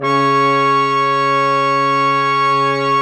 Index of /90_sSampleCDs/Roland LCDP06 Brass Sections/BRS_Quintet/BRS_Quintet % wh